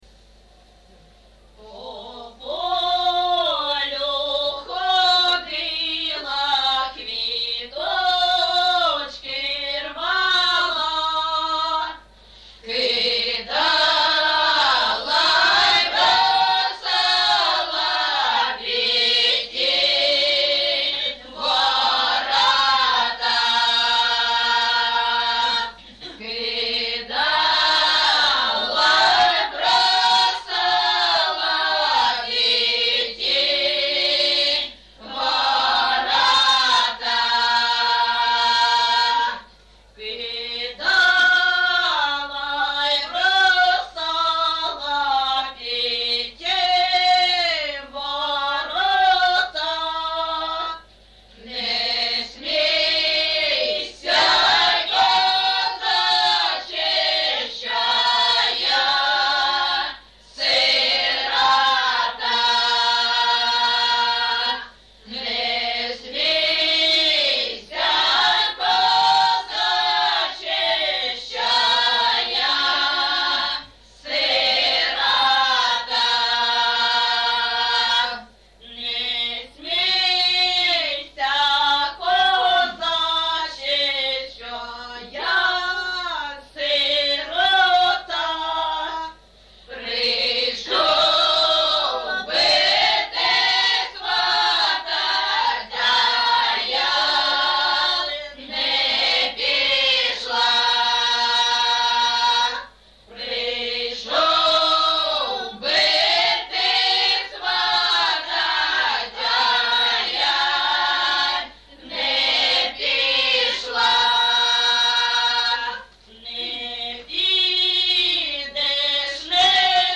ЖанрПісні з особистого та родинного життя
Місце записус-ще Щербинівка, Бахмутський район, Донецька обл., Україна, Слобожанщина